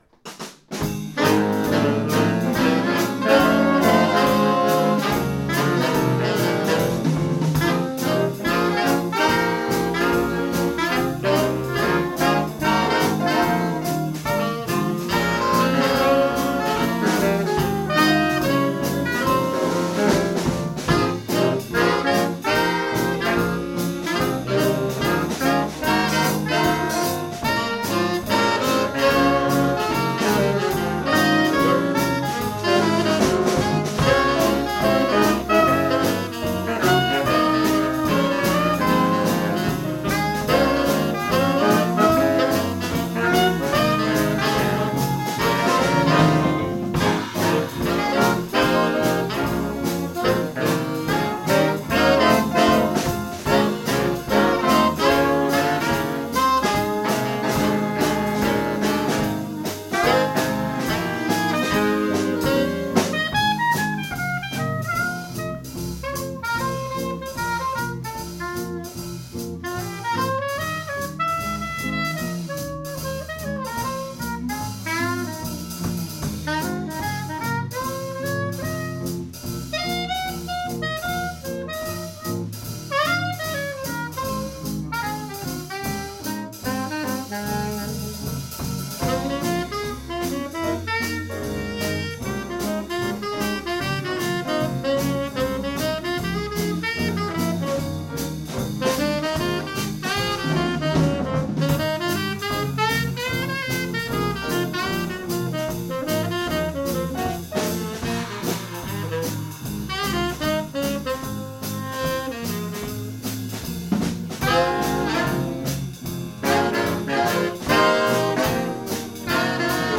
Jazz-nonetten på Lautrupgaard - optagelser 2025
Lyd i mp3-format, ca. 192 kbps, optaget stereo - ikke studieoptagelser!.